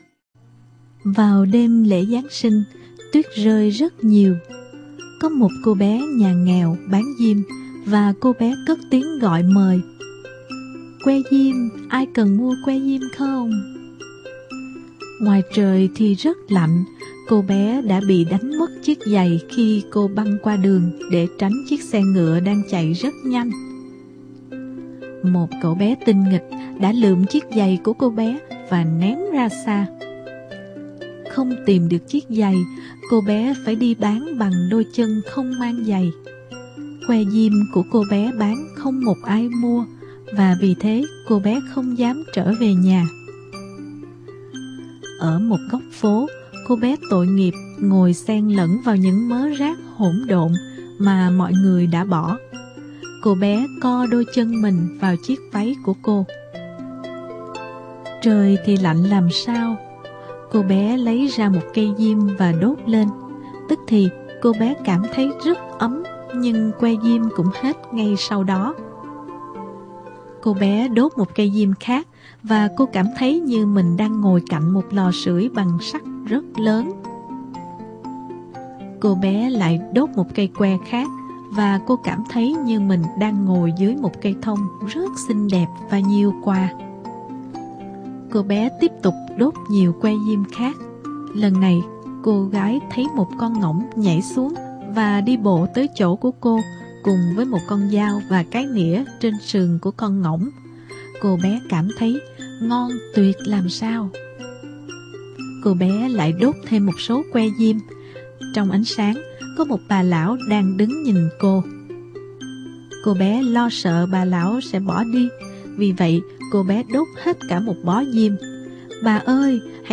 Sách nói | Cô bé bán diêm